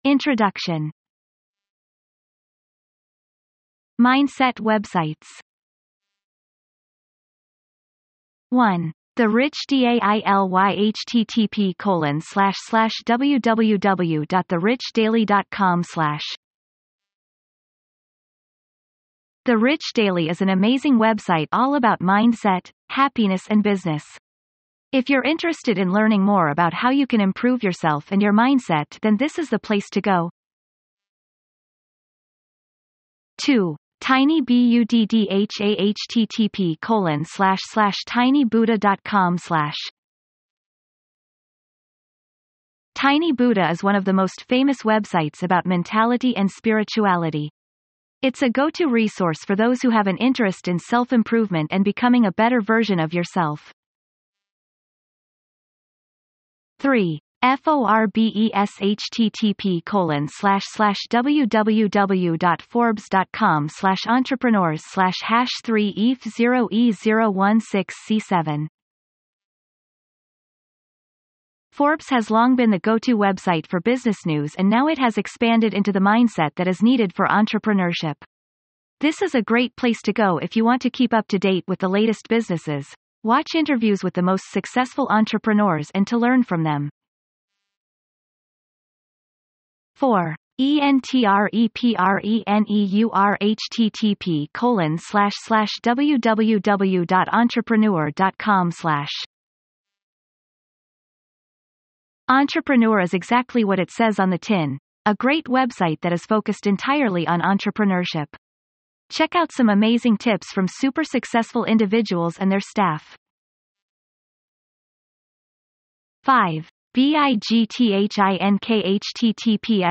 This is a handy resource report and audiobook on helping you or your audience better their mind to become more organized. Within this audio and report, you will find inside top tools for organizing your life, top blogs and forums, and also top tips and how to's.